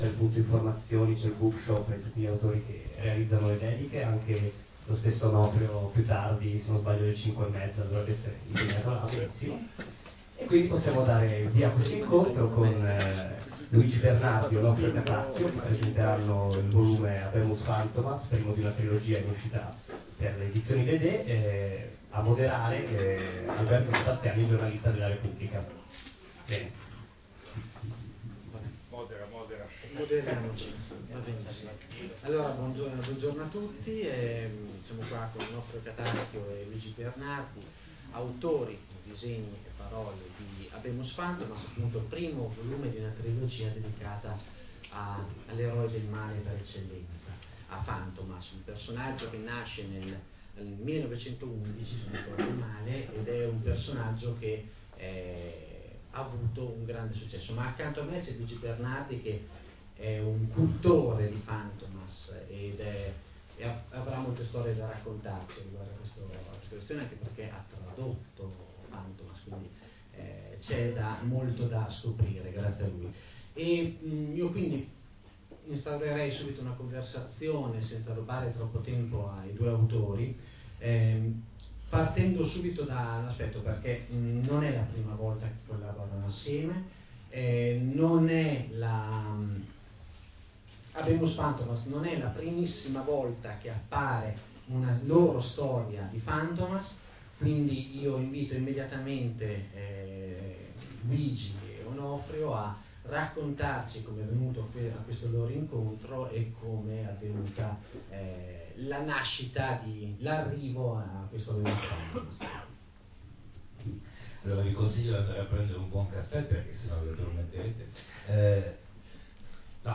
Scaricate qui sotto la diretta in mp3: Habemus Fantomas, diretta del incontro Condividi: Facebook Twitter Google Tumblr Pinterest E-mail Stampa Mi piace: Mi piace Caricamento...